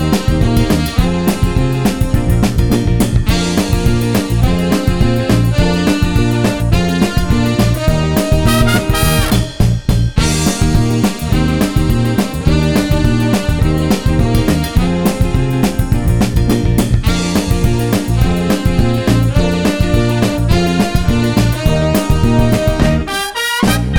Live Version Pop (1960s) 2:26 Buy £1.50